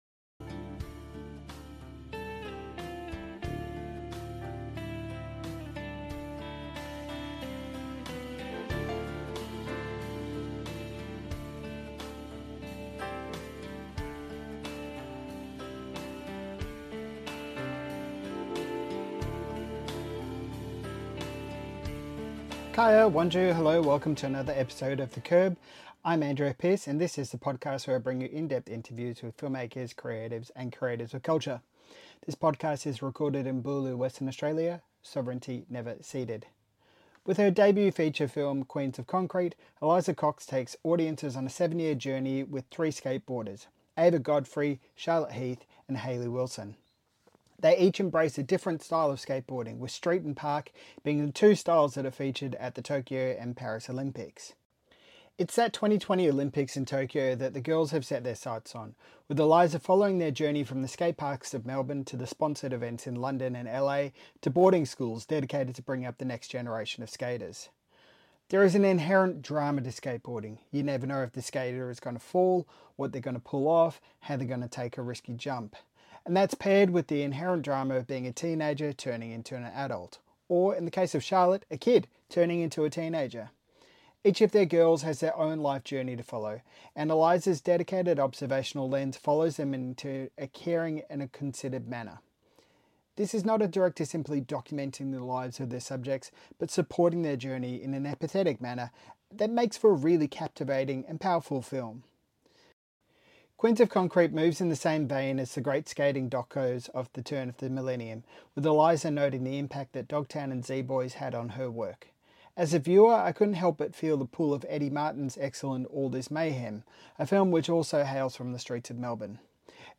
AIDC Interview